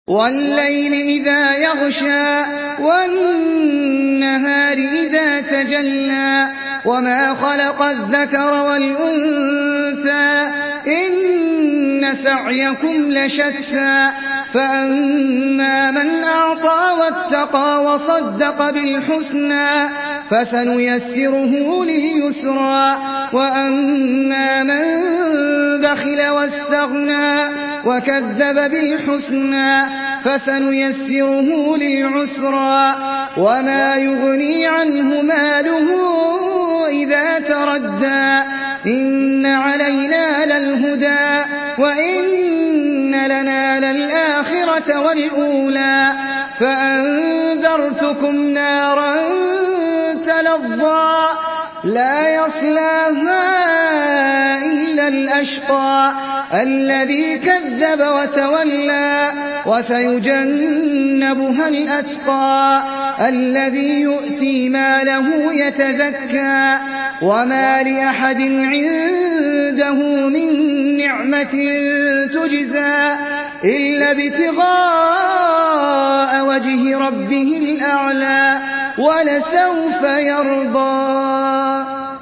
Surah Al-Layl MP3 by Ahmed Al Ajmi in Hafs An Asim narration.
Murattal